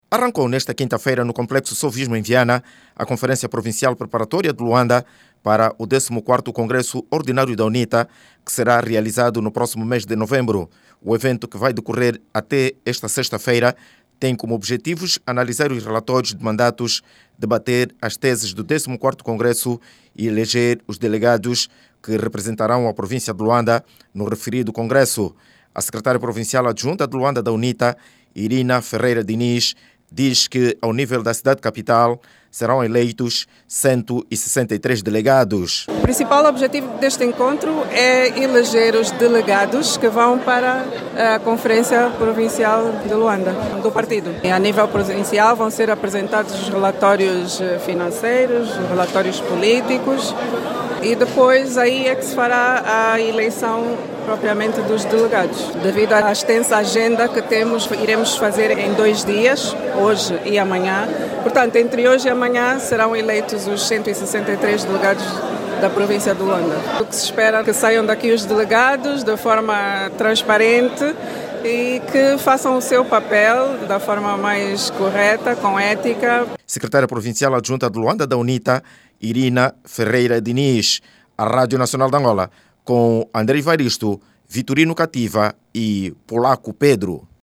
O partido do Galo Negro, deu hoje, quinta-feira, 23, início a conferência provincial em Luanda, que se estende até sexta-feira. A reunião, vai eleger os delegados que vão estar no conclave, que terá como ponto alto a eleição do novo Presidente da UNITA. Ouça no áudio abaixo toda informação com a reportagem